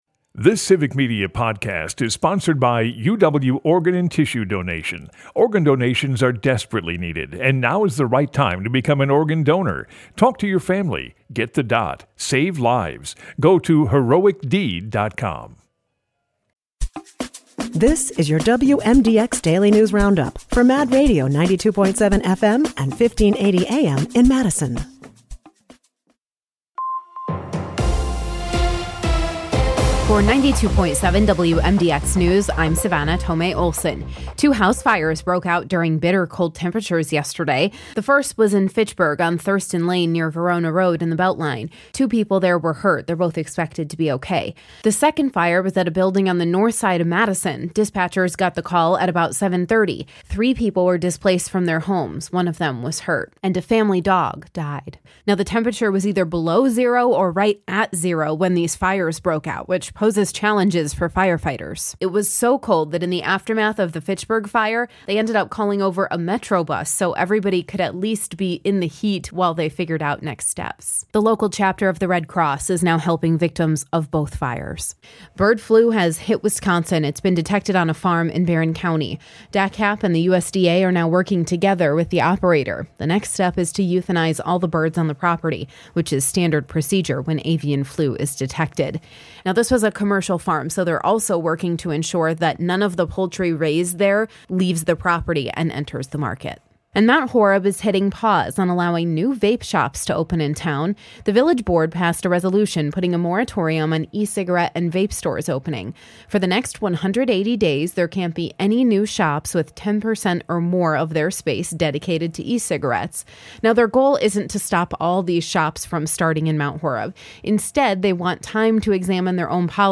The WMDX Mad Radio Daily News Roundup has your state and local news, weather, and sports for Madison, delivered as a podcast every weekday at 9 a.m. Stay on top of your local news and tune in to your community!